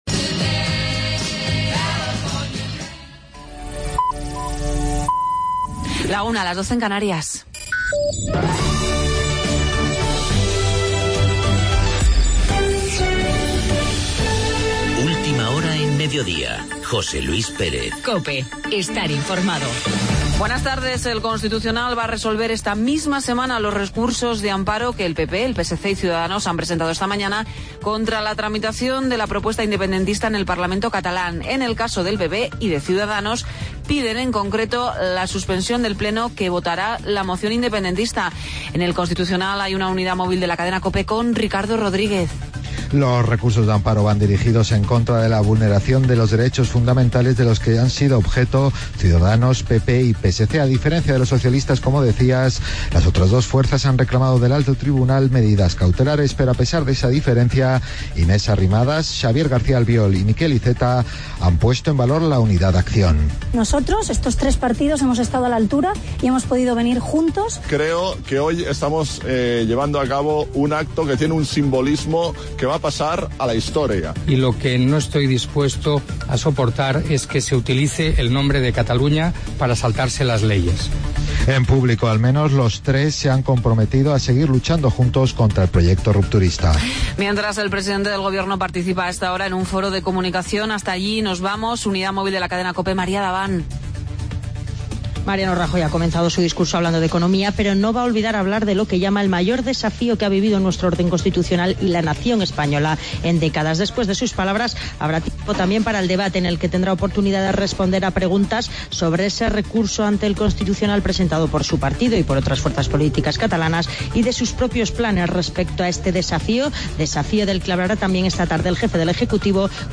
Redacción digital Madrid - Publicado el 04 nov 2015, 14:54 - Actualizado 19 mar 2023, 03:14 1 min lectura Descargar Facebook Twitter Whatsapp Telegram Enviar por email Copiar enlace Entrevista a Arabia Pérez Vales, portavoz del grupo municipal del PSOE en Palacios del Sil. Valoración sobre la dimisión del alcalde, Fernando Fernández Astorgano.